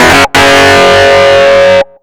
RIFFSYNT04-R.wav